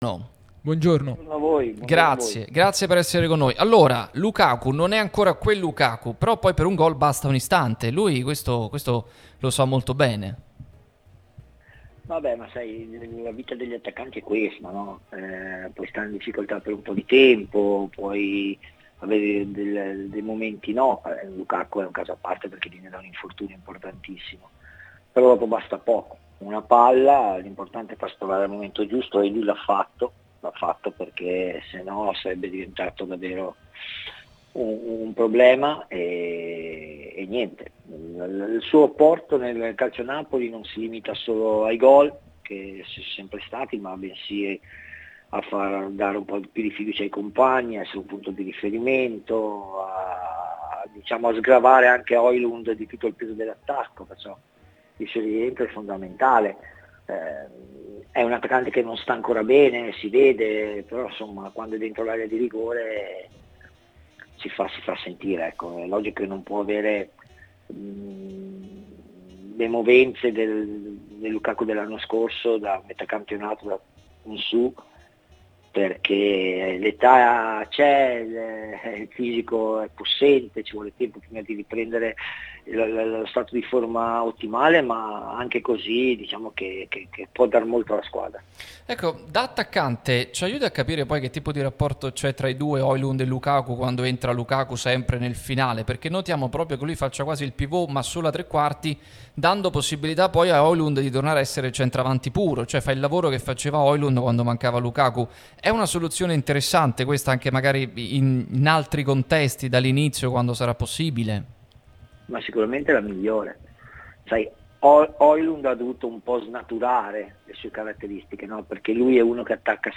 Stefan Schwoch, ex attaccante del Napoli, è intervenuto nel corso di "Pausa Caffè" sulla nostra Radio Tutto Napoli, prima radio tematica sul Napoli, in onda tutto il giorno, che puoi ascoltare/vedere qui sul sito o sulle app (qui per Iphone/Ipad o qui per Android): “Lukaku?